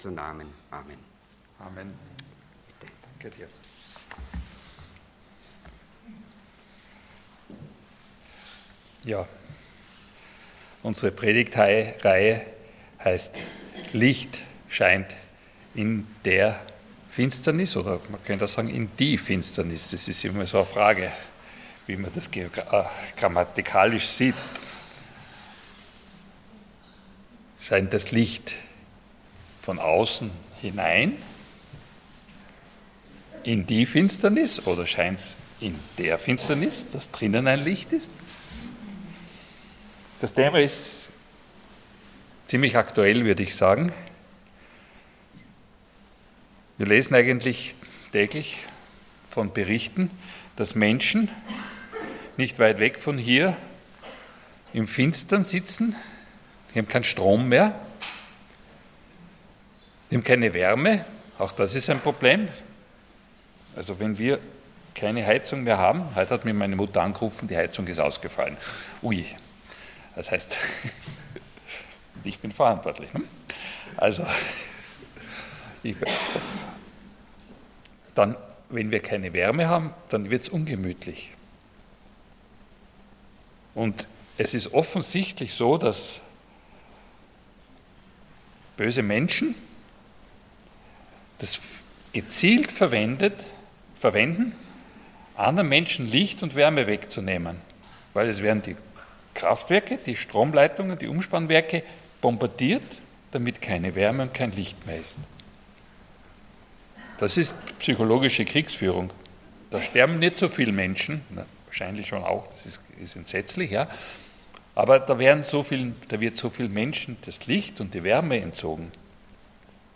Passage: John 1:1-12 Dienstart: Sonntag Morgen